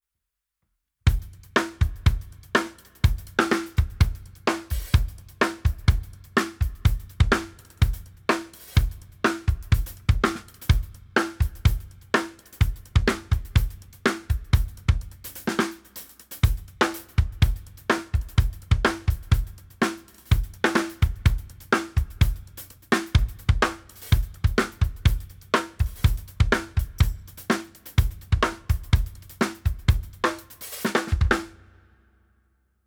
Un son bien LIVE et très réaliste.
C'est une tournerie du batteur alors ?
testdrums.wav